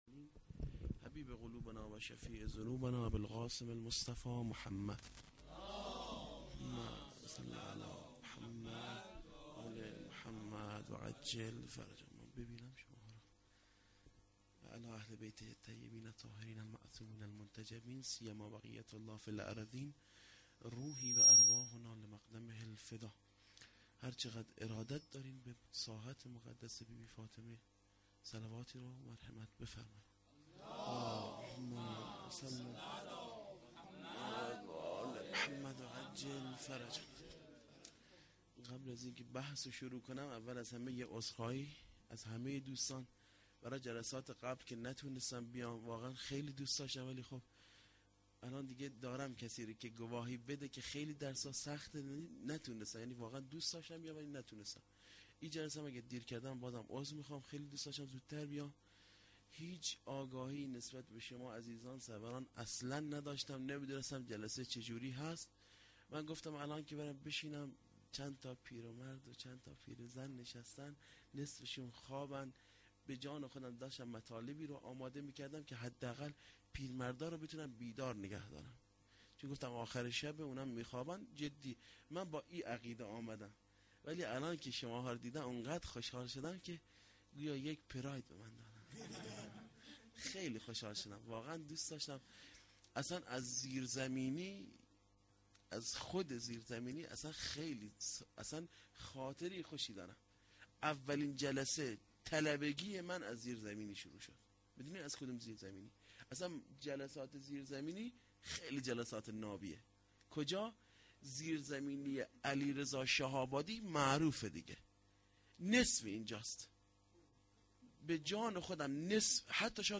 sokhanrani(haftegi92.12.14-rozatolabbas).mp3
روضه العباس